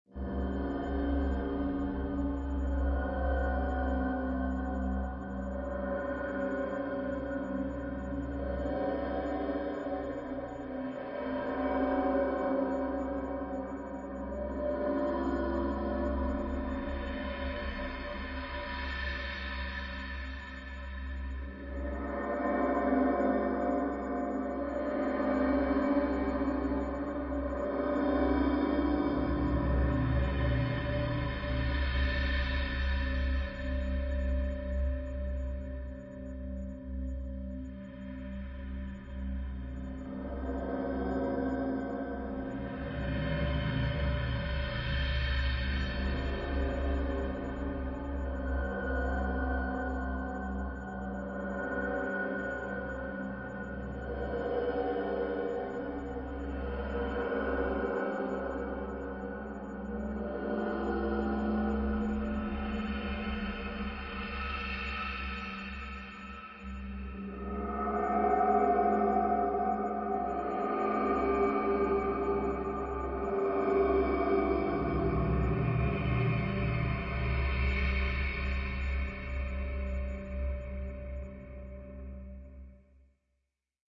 氛围 " 令人毛骨悚然的音景
描述：来自ImageLine的Sytrus FM合成器的倾斜垫。通过许多混响和去噪。将它调高八度以获得更多色调。根音是E
标签： 快感 实验 悬念 音景 怪异 闹鬼 氛围 环境 backgr ound 背景音 哥特式 ATMOS 戏剧 无人驾驶飞机 爬行 氛围 险恶 科幻 气氛
声道立体声